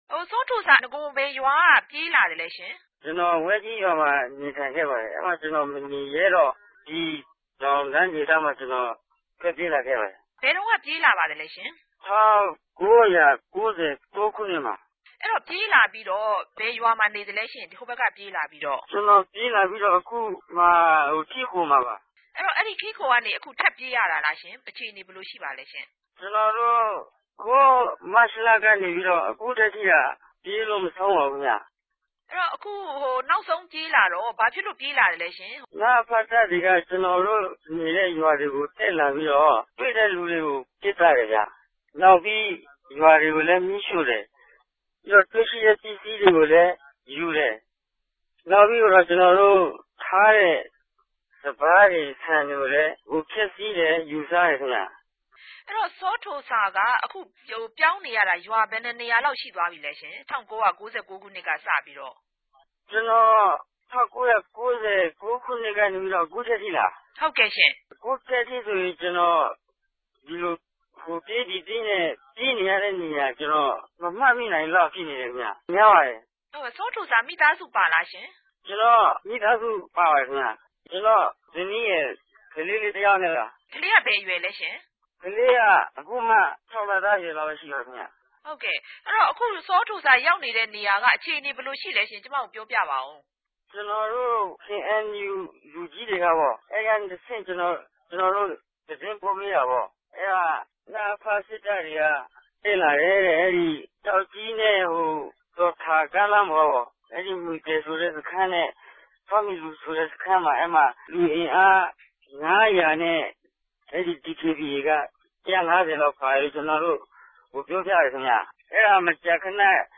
ဒီဒုက္ခသည်တေကြို ကူညီနေတဲ့ Free Burma Rangers ခေၞ ကူညီကယ်ဆယ်ရေးအဖြဲႚကို RFA က ဆက်သြယ် မေးူမန်းခဲ့ရာမြာ ညောင်လေးပင်နဲႛ တောင်ငူခ႟ိုင်ထဲက လက်ရြိ အိုးမဲ့ အိမ်မဲ့ ဒုက္ခသည်တေရြဲ့ လက်ရြိ အေူခအနေနဲႛ အရေအတြက်ကို Free Burma Rangers အဖြဲႚဝင်တယောက်က ေူပာူပခဲ့ပၝတယ်။